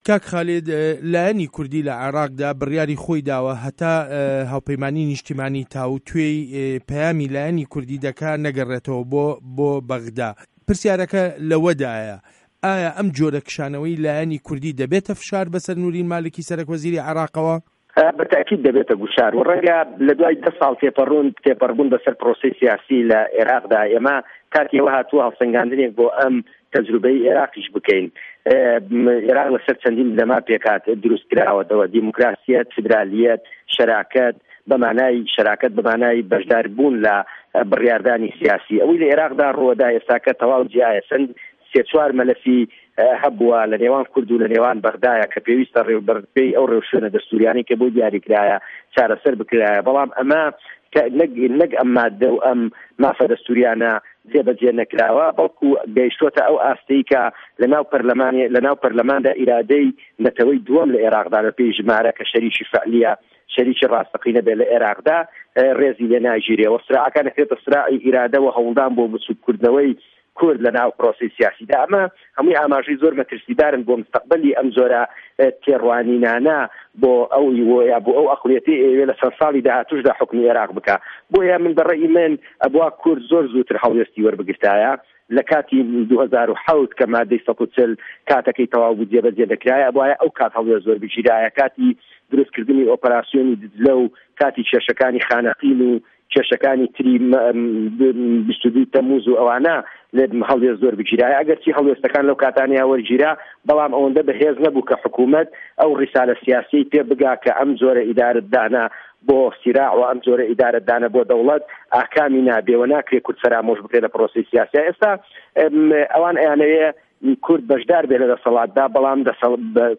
وتووێژ له‌گه‌ڵ خالید شوانی